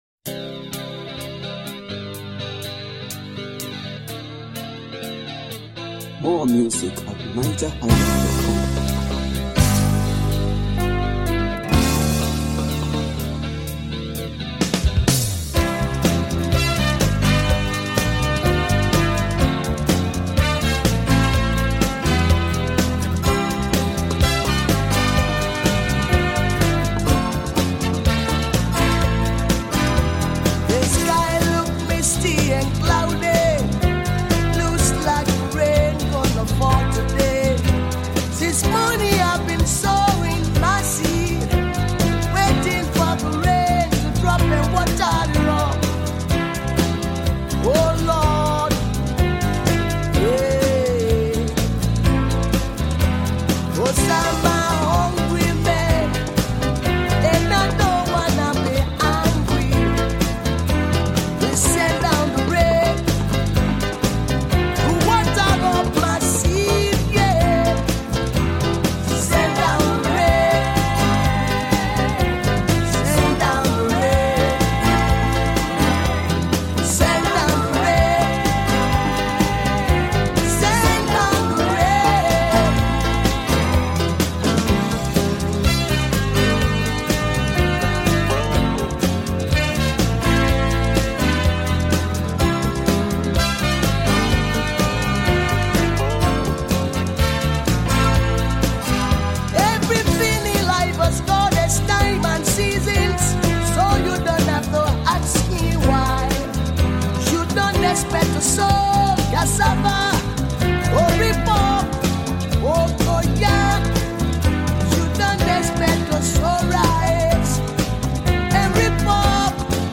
Home » Ragae
Nigerian Reggae Music